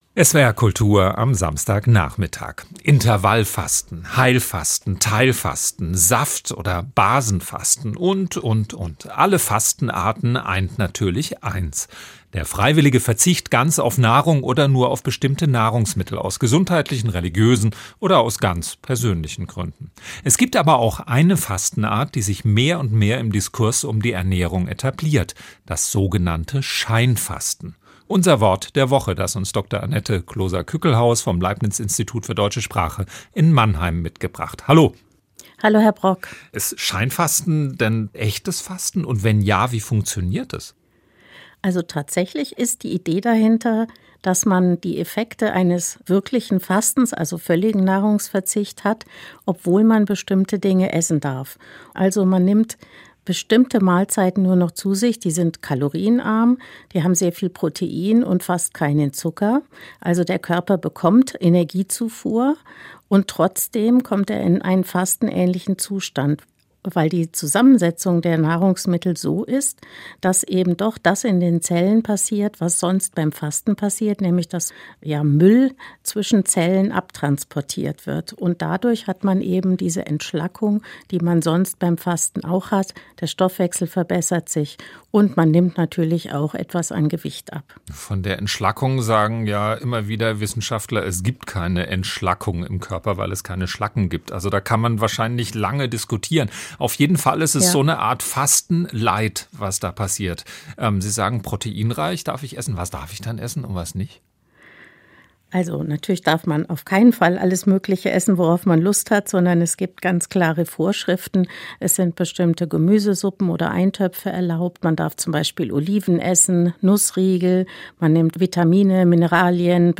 Gespräch mit